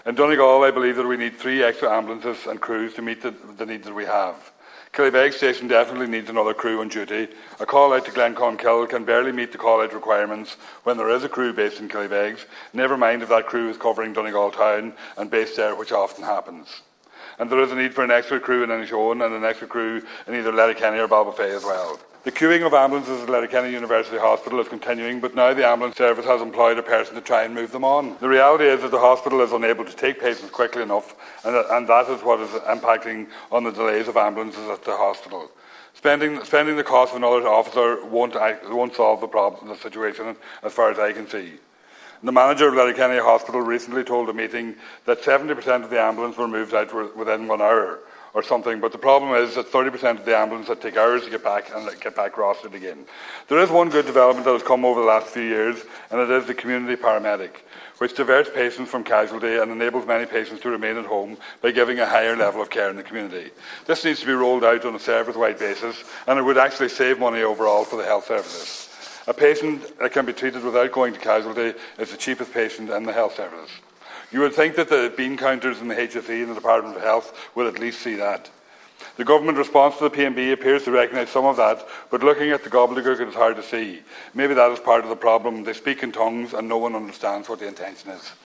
Donegal Deputy Thomas Pringle has told the Dail that the ambulance service is in crisis, similar to the rest of the health care system.
Deputy Pringle says there are simply not enough resources available in the county to deal with the demand on the service: